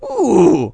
mp_ooooh.wav